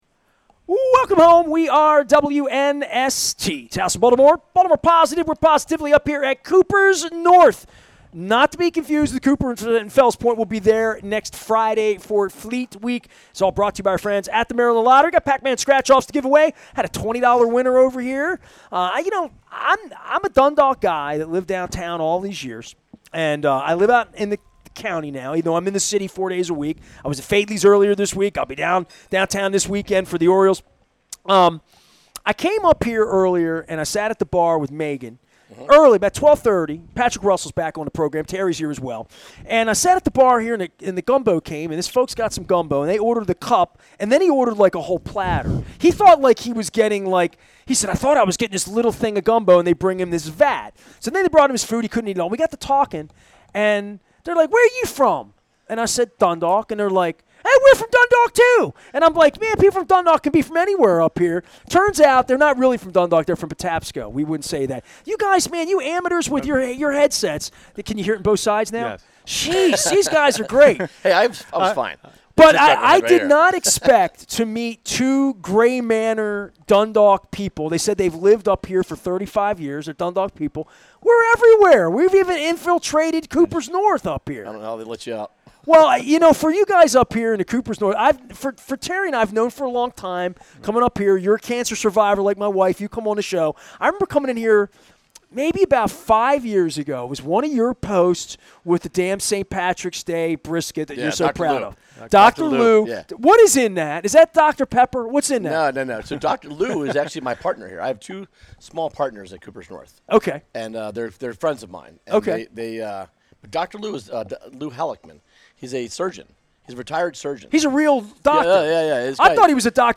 As the Maryland Crab Cake Tour presented by the Maryland Lottery, Liberty Pure Solutions and Jiffy Lube returned to Kooper's North